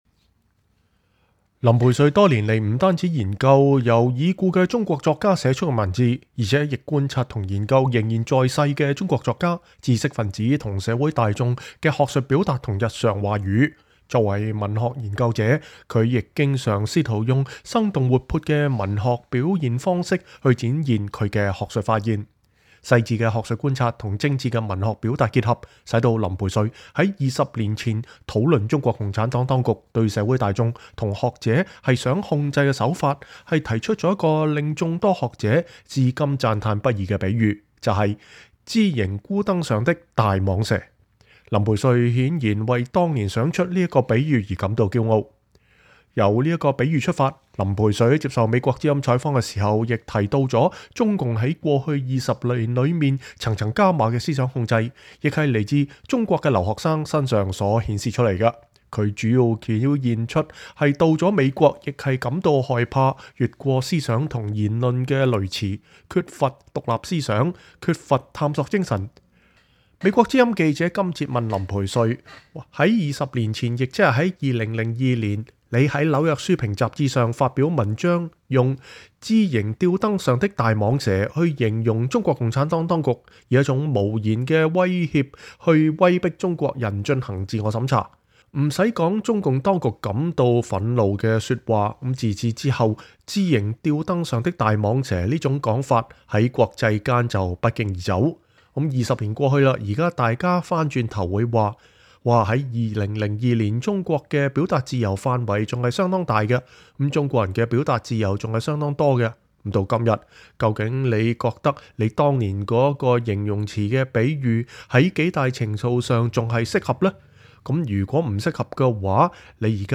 專訪林培瑞（3）：談中共當局的言論控制